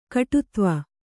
♪ kaṭutva